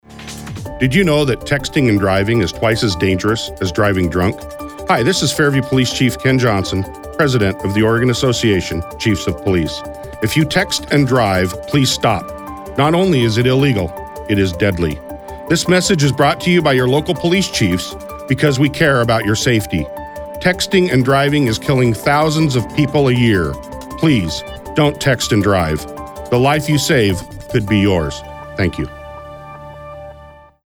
OACP Texting While Driving PSA